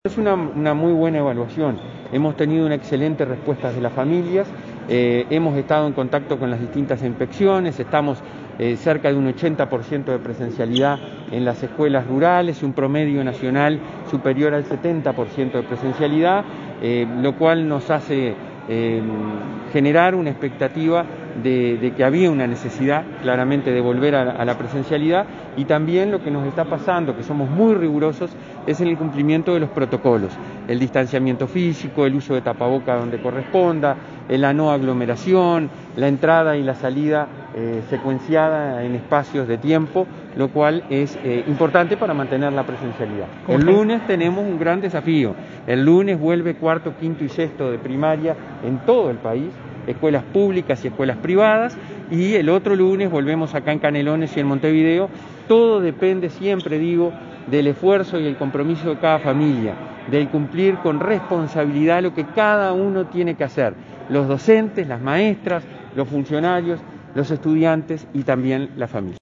El presidente del Codicen, Robert Silva, participó en la inauguración de un polideportivo en la localidad de Progreso en Canelones. En rueda de prensa Silva hizo una evaluación sobre la vuelta a la presencialidad y dijo que es «muy buena».